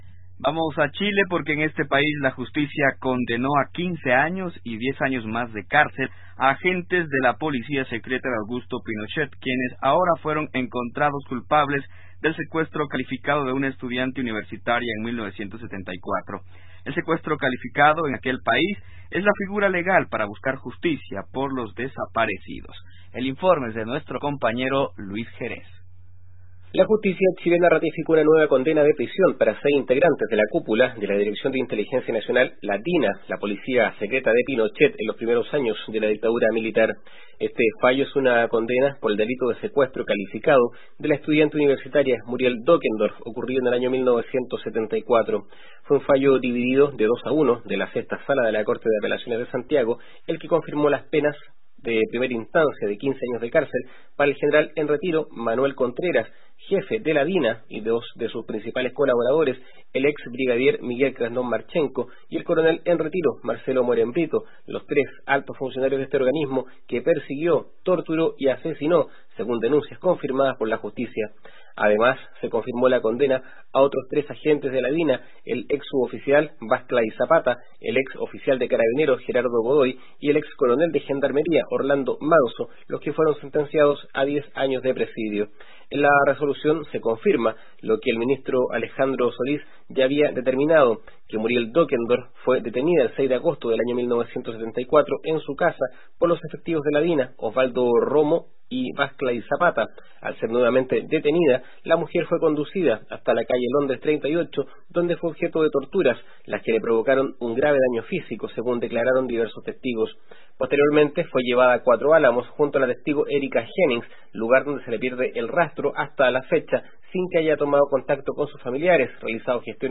Esta es otra nota contextuada tomada del programa Contacto Sur de ALER.